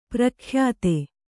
♪ prakhyāte